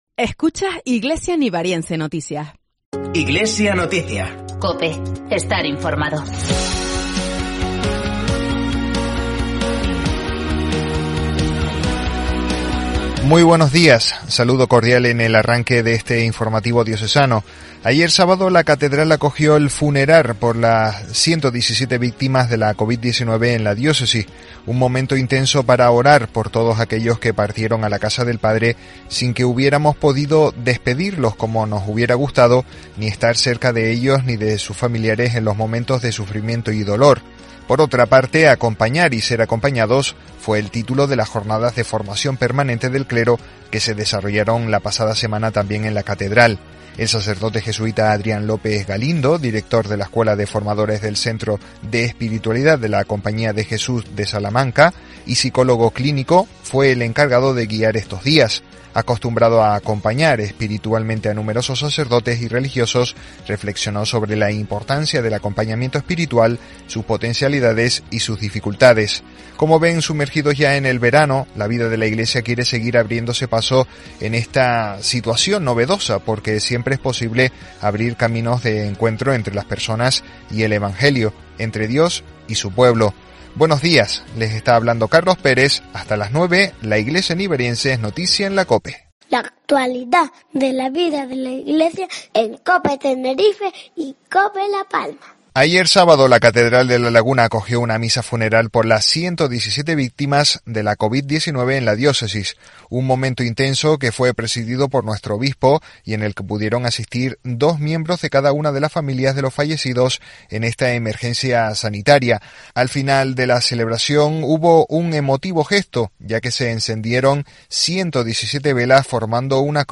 Como comenta uno de los familiares, ha sido una oportunidad para estar más cerca de sus seres queridos y superar la pérdida, además de poder por fin celebrar una eucaristía en su recuerdo.